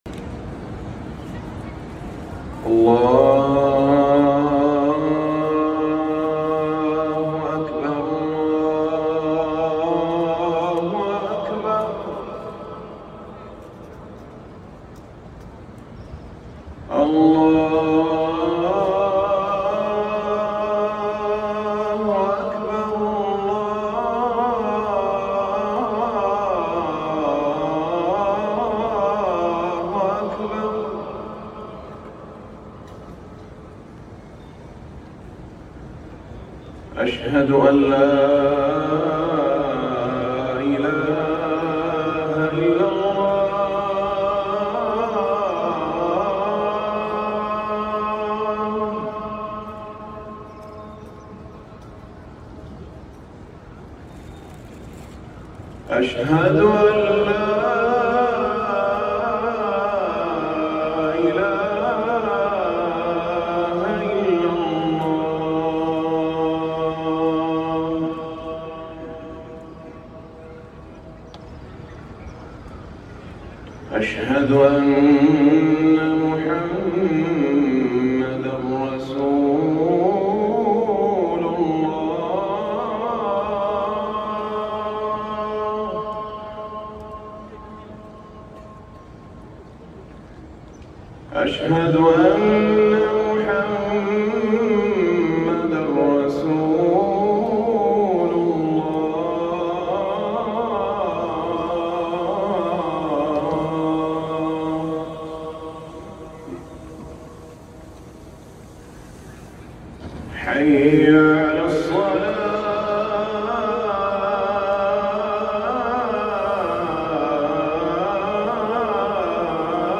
أذان الفجر الأول
الأذان الأول لصلاة الفجر